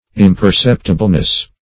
-- Im`per*cep"ti*ble*ness, n. -- Im`per*cep"ti*bly, adv.